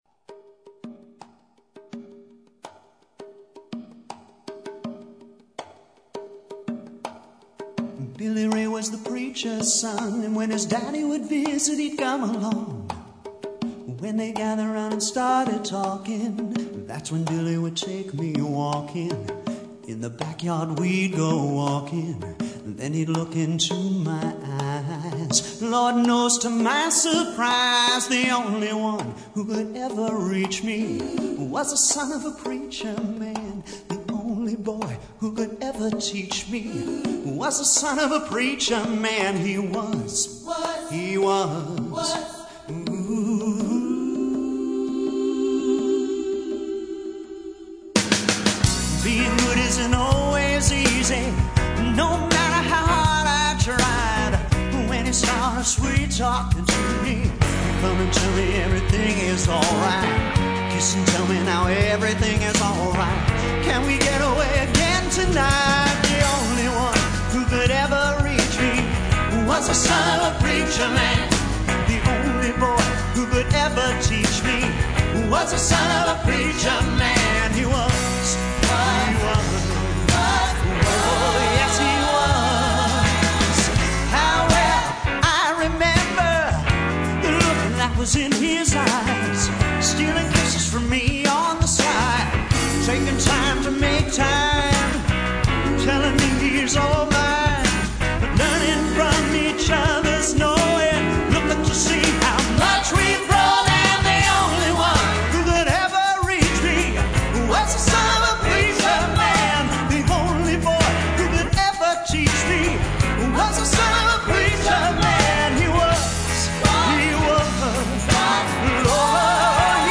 Part 2 August 2006 See page 3 for links to the artists Songs I've Been Meaning to Play, Part 2 This is a continuation of my June show, only things got more out of hand...this time there are three hours of songs covering many genres, decades, and subjects, including love songs, politics. comedy and spoken word.